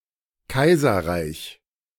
2. ^ German: [ˈkaɪzɐʁaɪç]